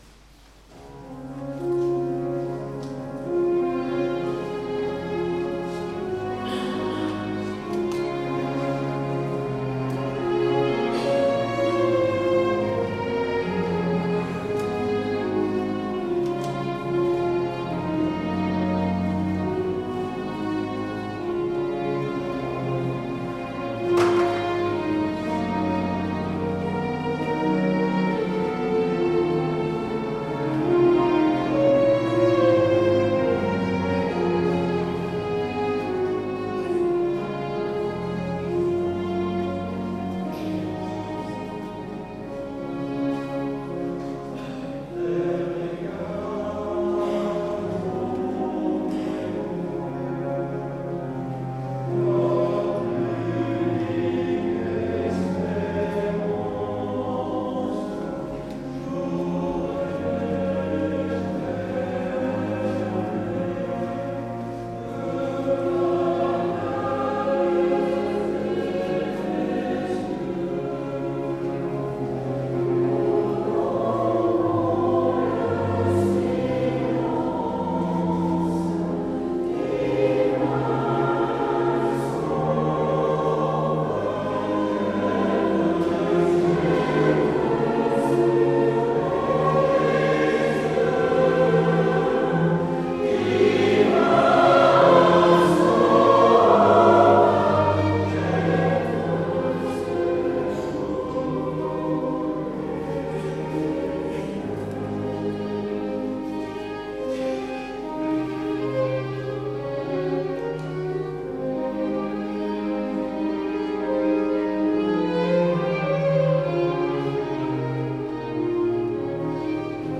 9 Novembre Eglise du Val d'Ajol Concert avec le quatuor à cordes Alliance